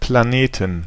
Ääntäminen
Ääntäminen Tuntematon aksentti: IPA: /plaˈneːtn/ Haettu sana löytyi näillä lähdekielillä: saksa Käännöksiä ei löytynyt valitulle kohdekielelle. Planeten on sanan Planet monikko.